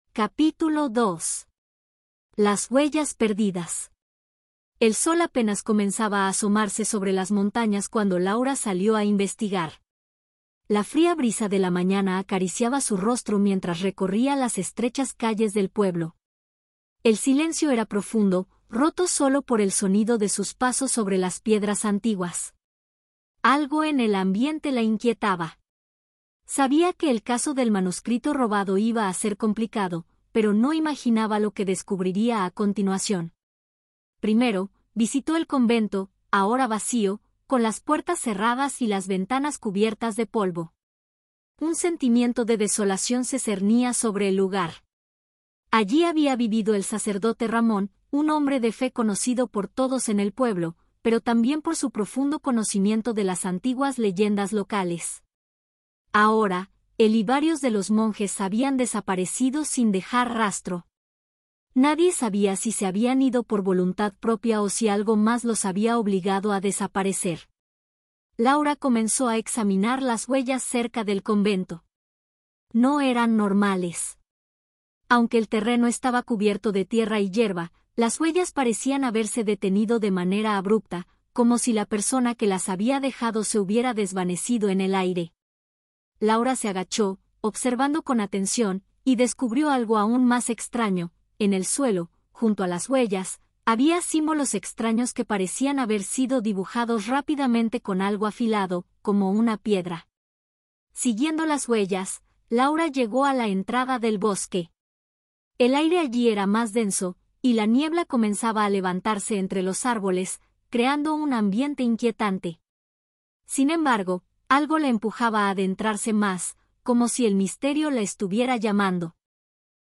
AudioBook El misterio del manuscrito B2-C1 - Hola Mundo